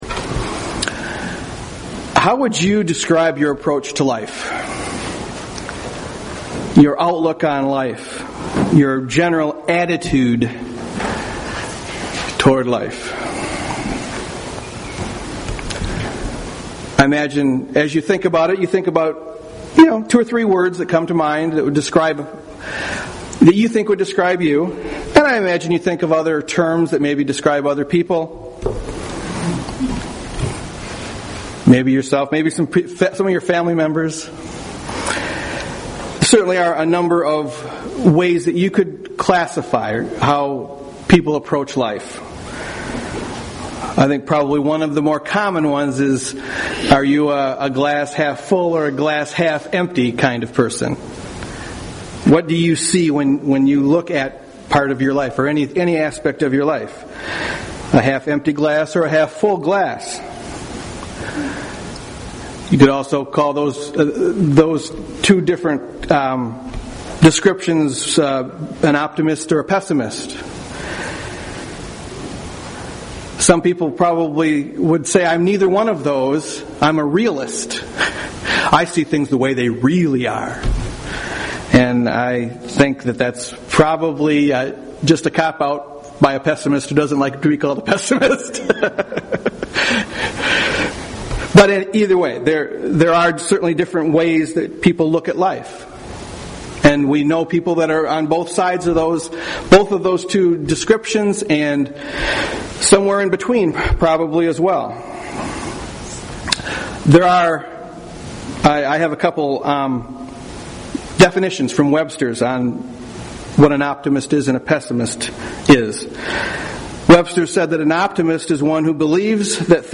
UCG Sermon Studying the bible?
Given in Grand Rapids, MI